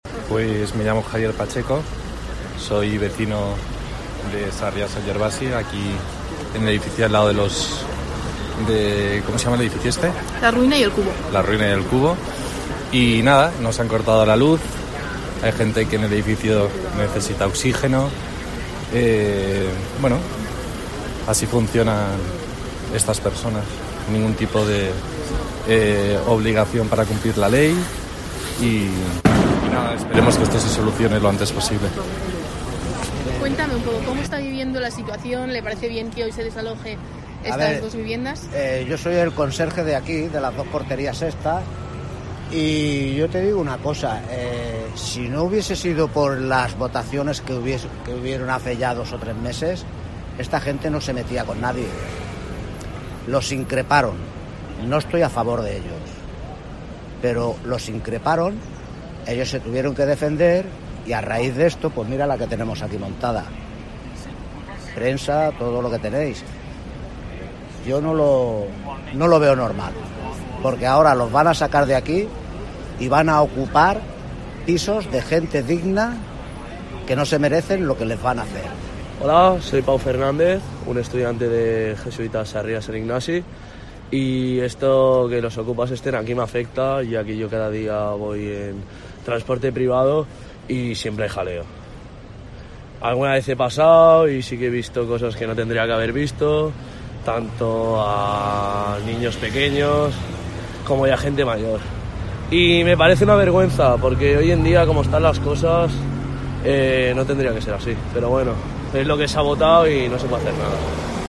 Los vecinos opinan sobre el desalojo de el Kubo y la Ruina
Un vecino de la zona se muestra indignado por el comportamiento de estos okupas, ya que, afirma, no sienten ninguna obligación por cumplir la ley.